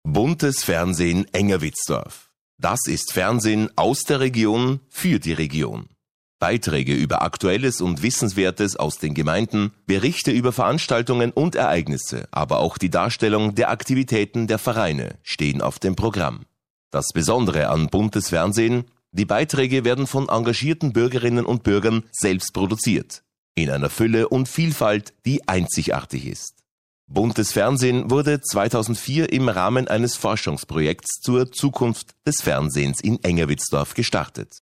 Profi-Sprecher deutsch.
Sprechprobe: Werbung (Muttersprache):
german voice over talent